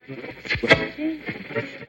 radio.mp3